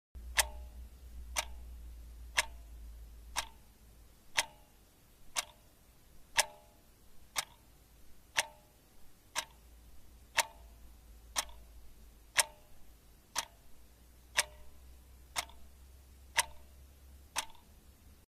جلوه های صوتی
دانلود صدای تیک تاک ساعت 4 از ساعد نیوز با لینک مستقیم و کیفیت بالا